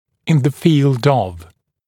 [ɪn ðə fiːld əv][ин зэ фи:лд ов]в области